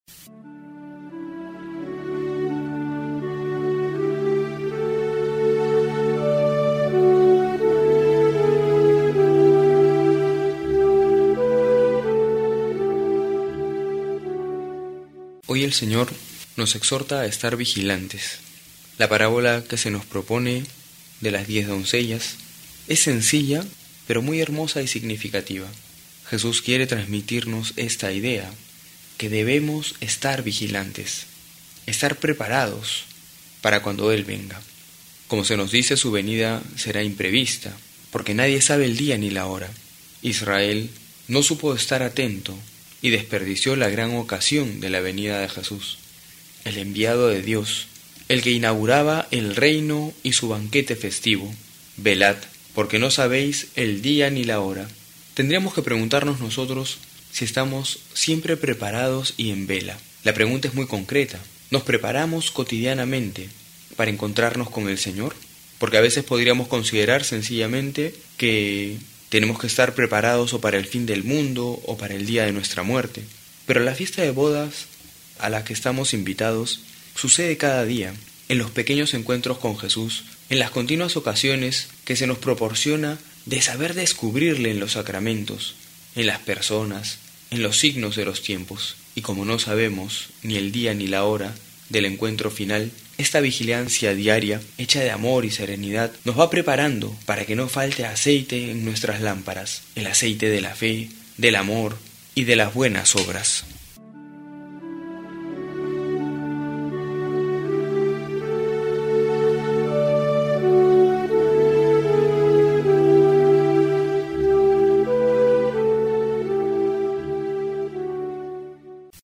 agosto31-12homilia.mp3